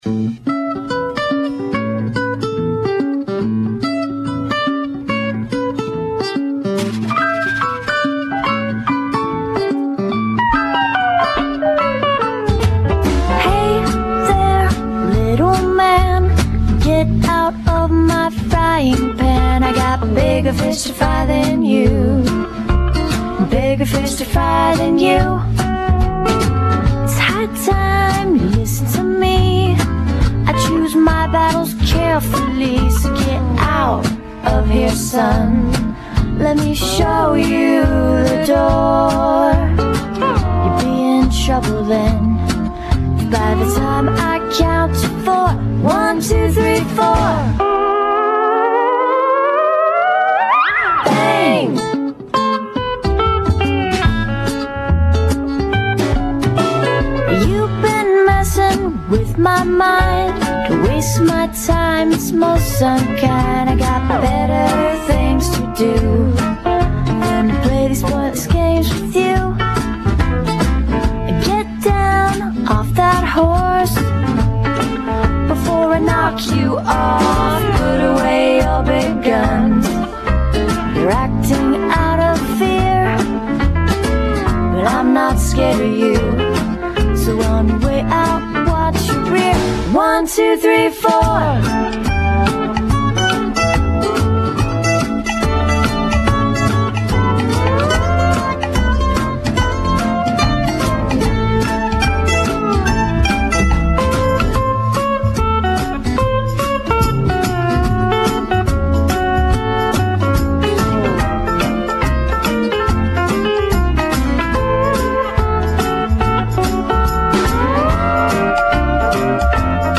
I feel very fortunate to have a chance to do an interview with James Jean . James is currently most known for his fantastic covers of the Vertigo series, Fables.